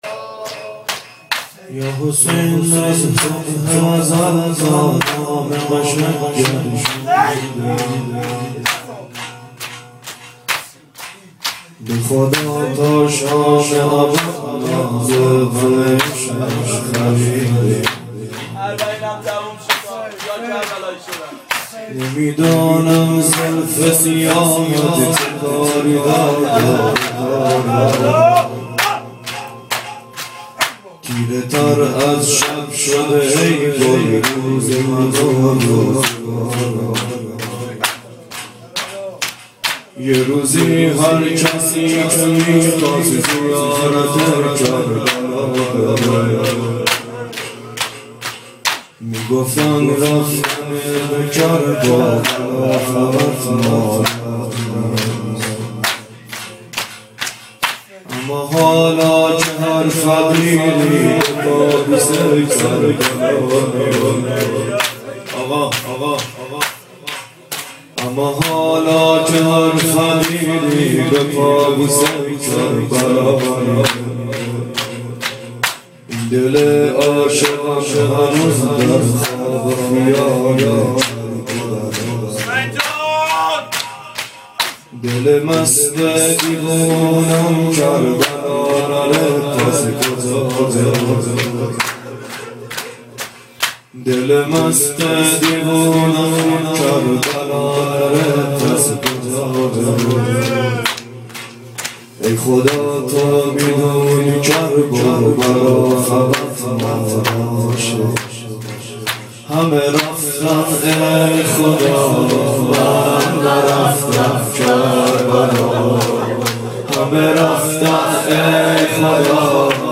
• ظهر اربعین سال 1390 محفل شیفتگان حضرت رقیه سلام الله علیها